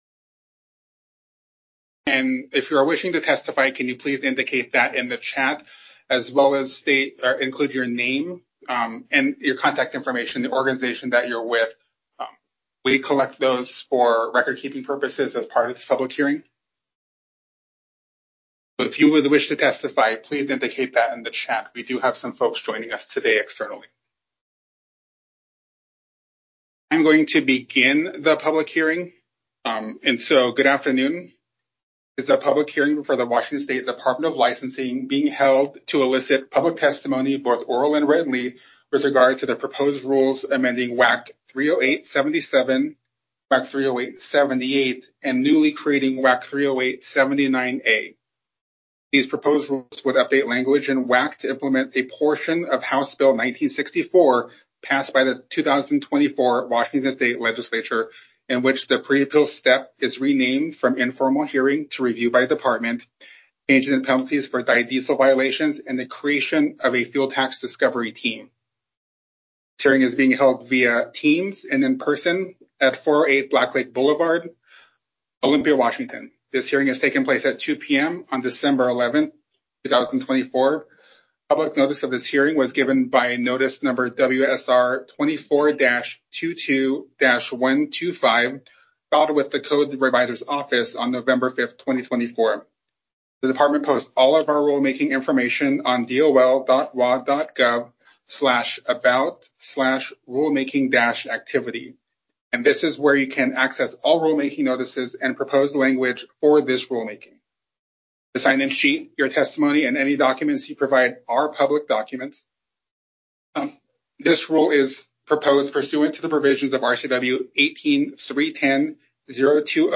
Public hearing or comments
We held a public hearing for this rulemaking activity on December 11, 2024.
Enhanced prorate and fuel tax collection public hearing audio recording (MP3, 6 minutes) Public hearing audio transcript